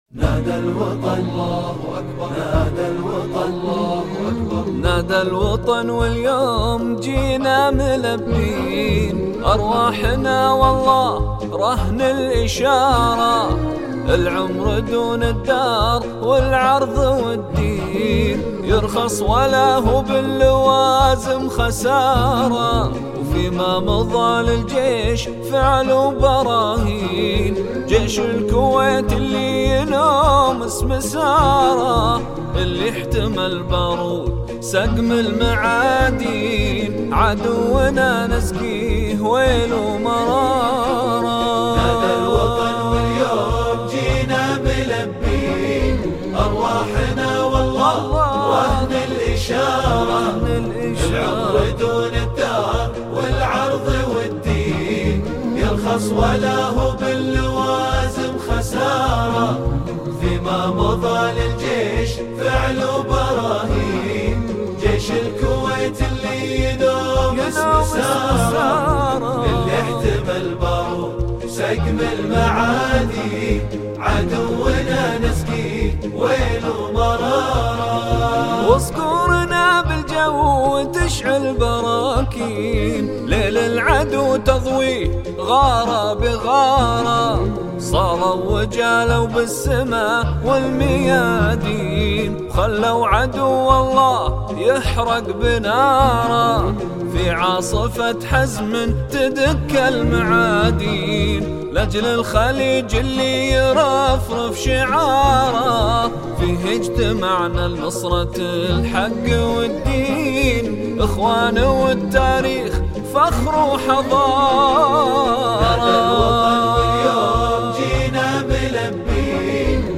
نشيدة